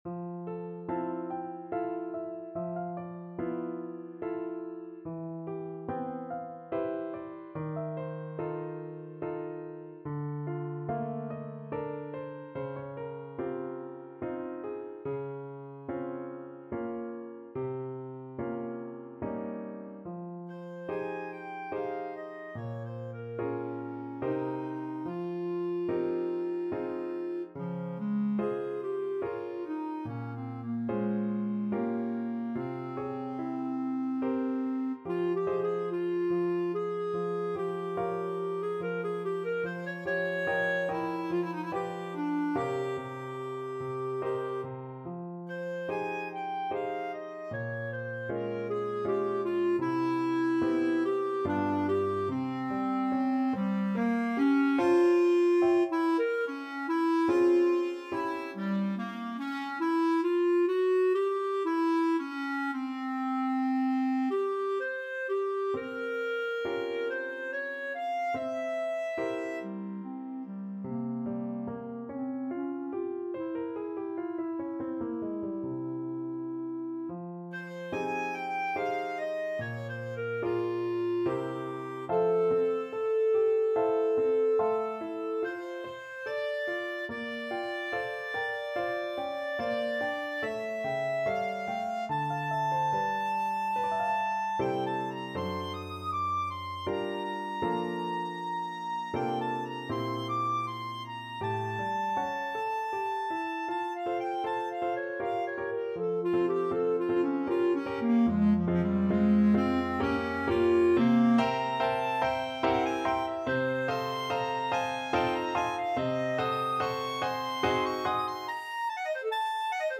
Clarinet
F minor (Sounding Pitch) G minor (Clarinet in Bb) (View more F minor Music for Clarinet )
Andante =72
Classical (View more Classical Clarinet Music)